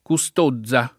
kuSt1zza, regolare continuaz. del lat. custodia con -o- lungo; freq. nelle altre regioni anche la tendenza a una pn. con -z- sorda per attraz., ancóra in questo, dei nomi alterati in -ozza, -ozzo